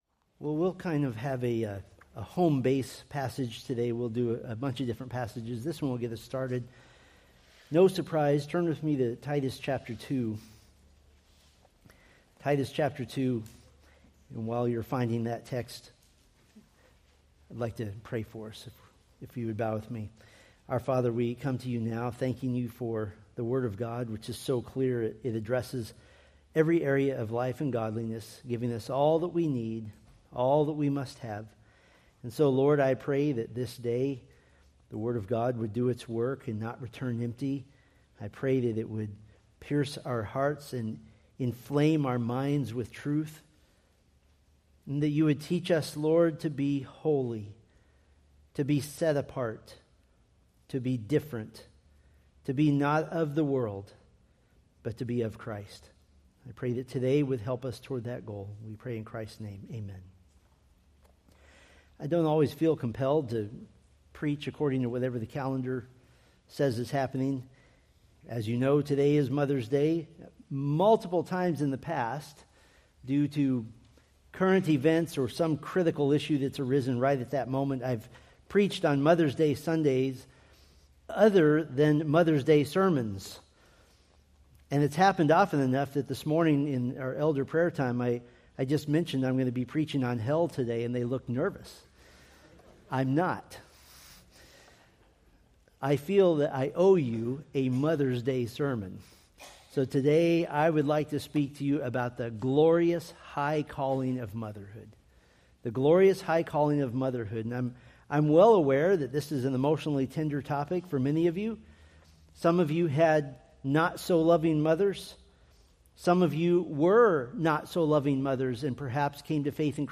Preached May 11, 2025 from Titus 2:3-4; 1 Timothy 5:14